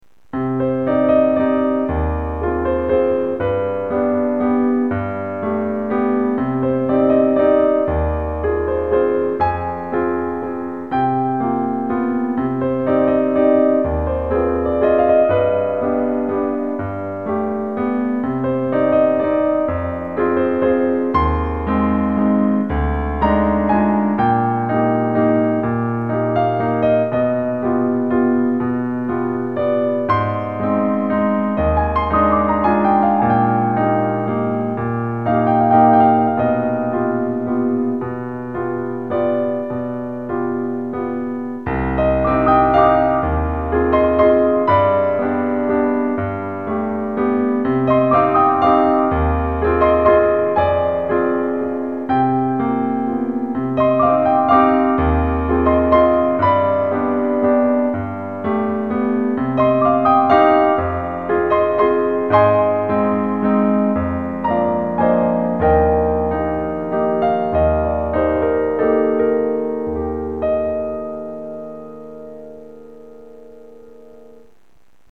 Mon piano : Seiller 116 Duovox